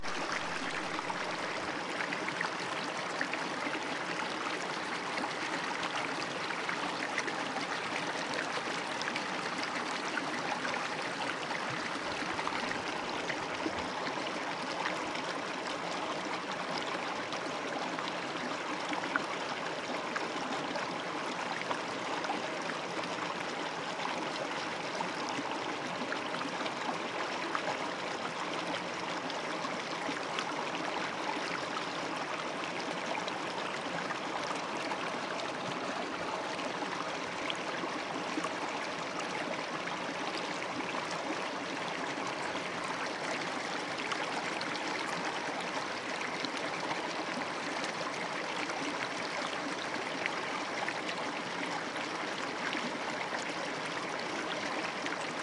Montseny " creek Montseny
描述：卡诺瓦斯水库旁边的小溪（西班牙蒙特塞尼）。用索尼PCMD50录音
标签： 场记录 性质
声道立体声